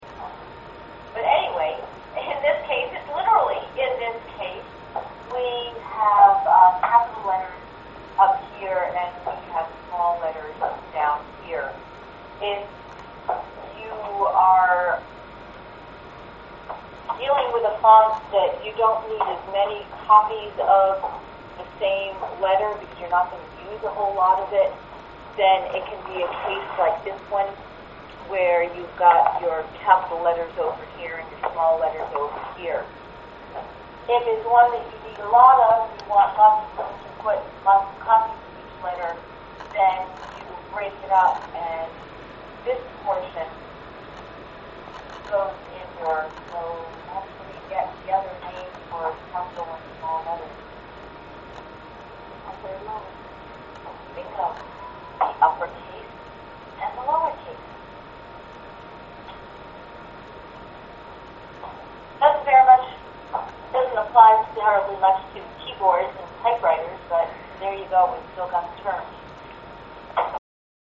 Und in der originalgetreu ausgestatteten Druckerei erfahren wir, wie die Begriffe "uppercase" und "lowercase" für Gross- und Kleinbuchstaben zustande kamen - wegen dem Satzkasten.
museum-lowercase.mp3